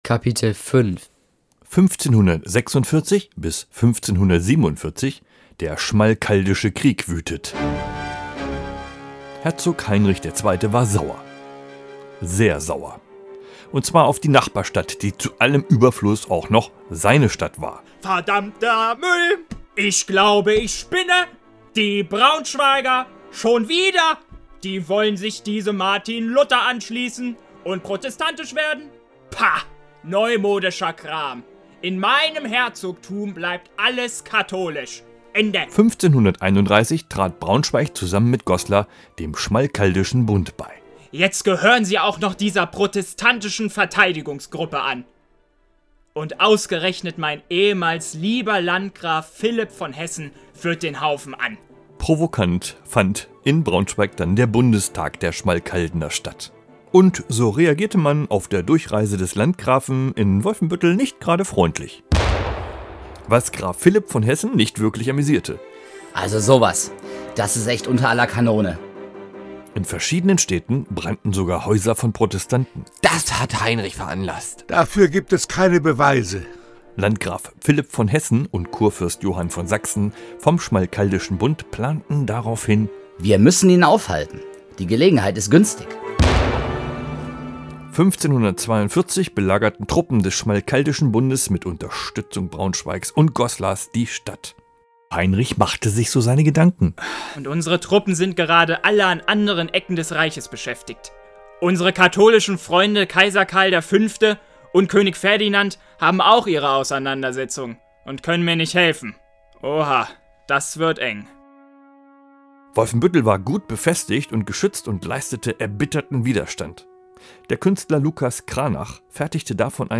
900 Jahre Wolfenbüttel – Das Hörspiel zum Comic | Studio-Regenbogen